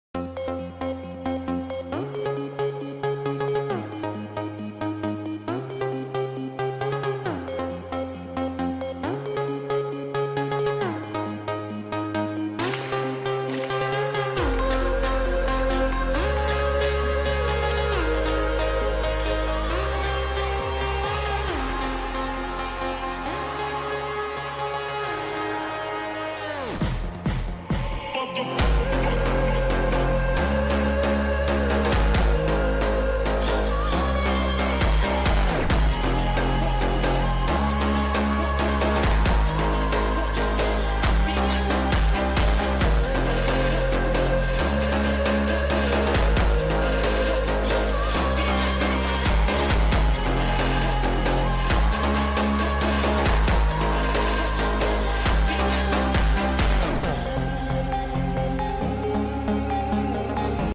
powerful bass